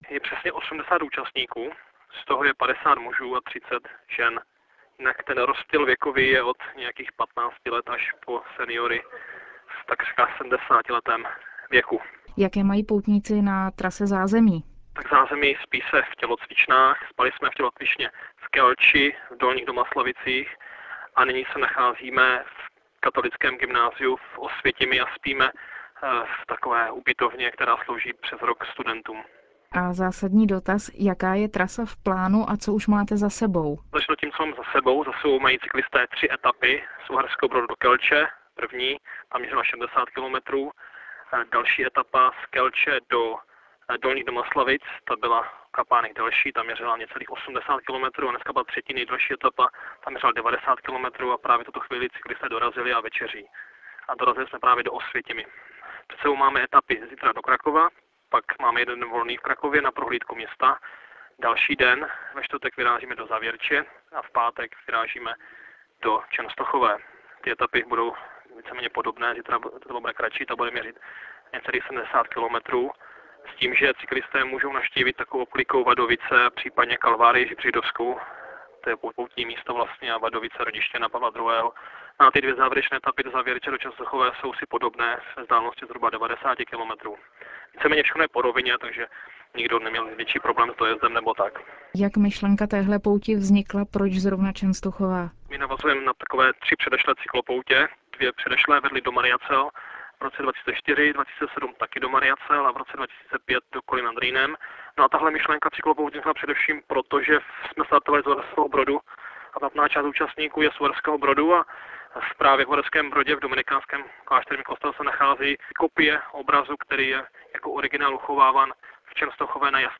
Včera večer poutníci dorazili do Osvětimy, kam jsme se s nimi telefonicky spojili.